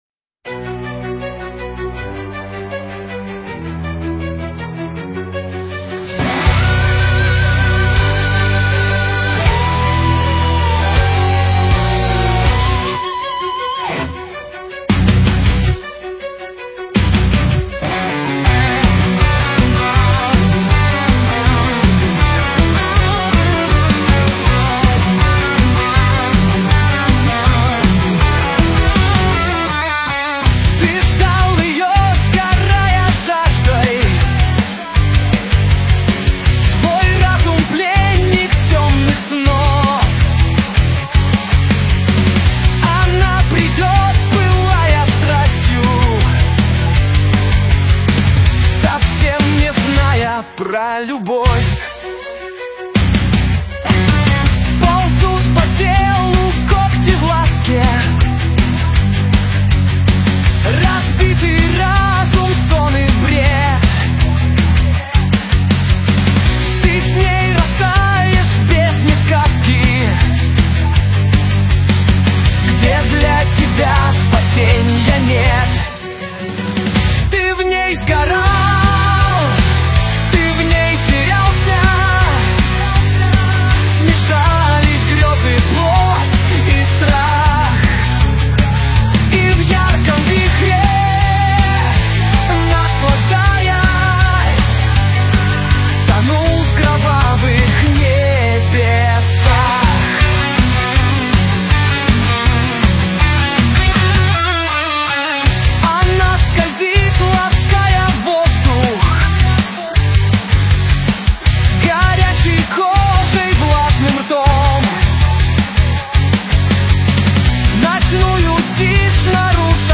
Avtorskaja_pesnja_Metal_Plennik_tjomnyh_snov.mp3